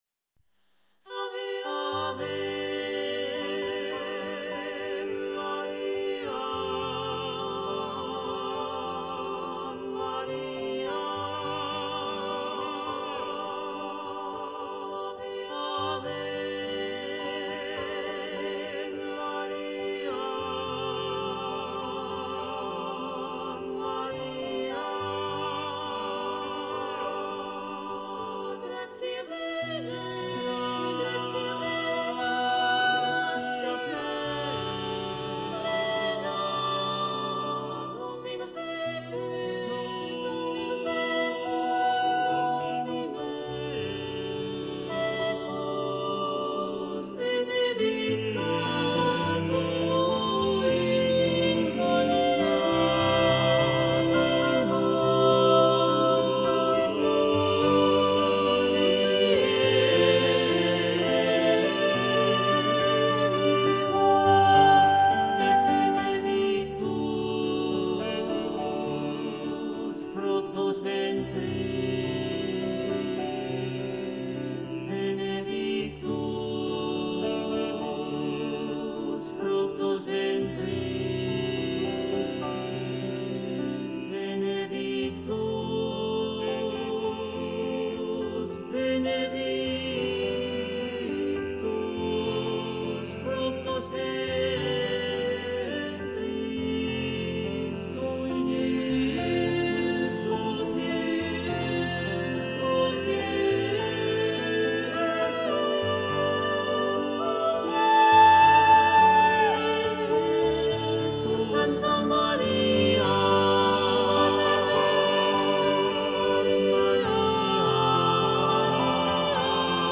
Skladba pro soprán, smíšený sbor a klavír.
demo     notový materiál